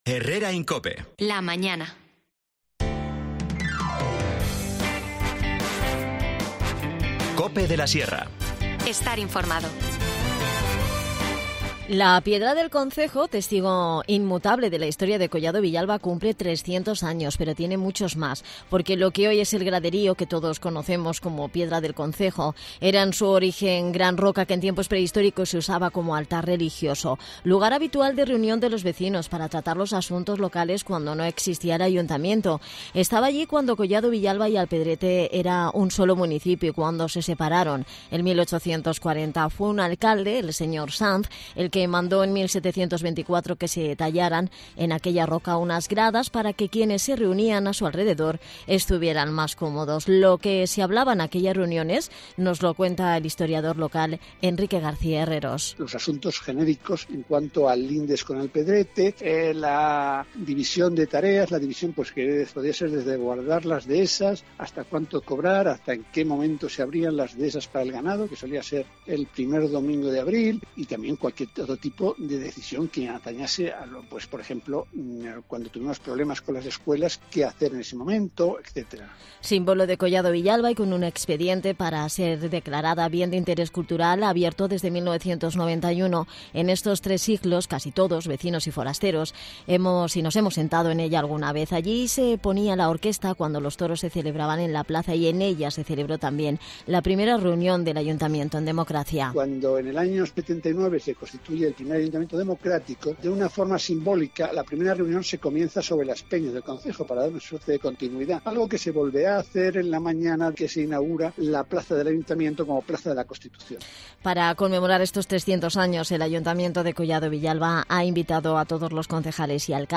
Nos cuenta todos los detalles Áurea Manso de Lema, tercer teniente alcalde y concejal de Entorno Rural.